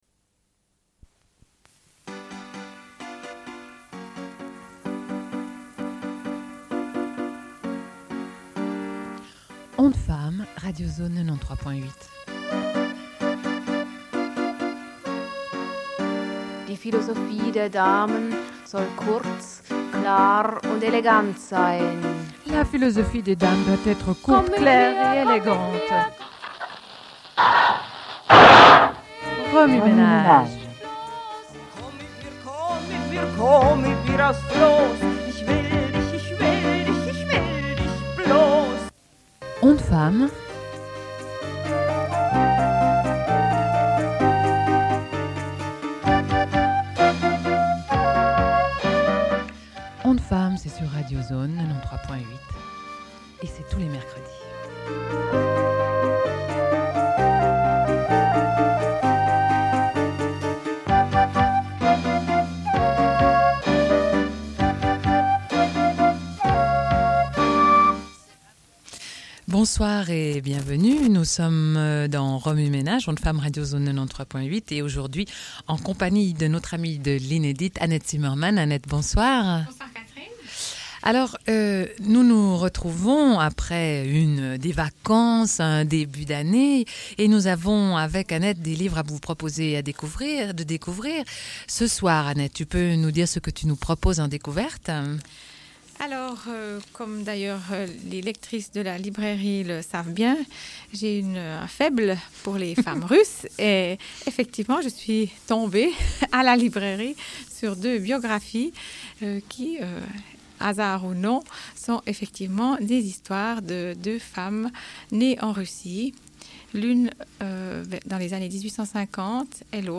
Une cassette audio, face A31:26